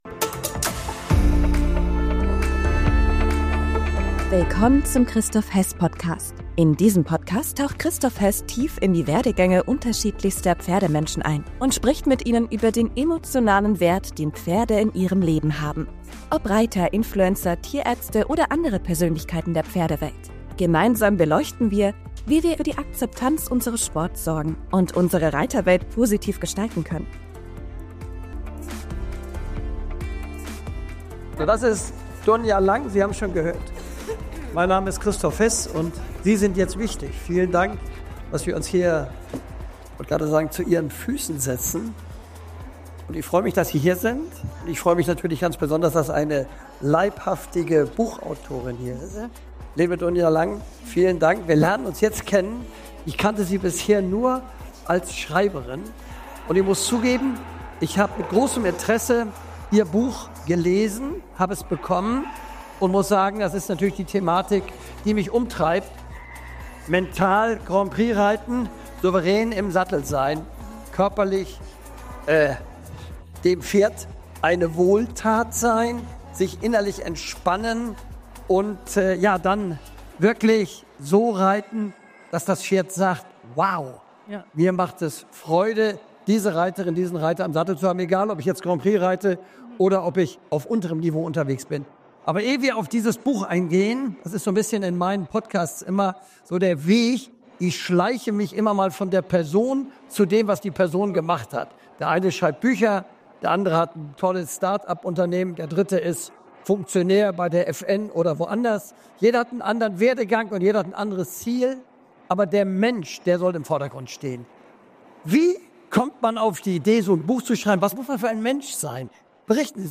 Diese Podcastfolge wurde live auf der Vision Stage der Messe Passion Pferd aufgezeichnet.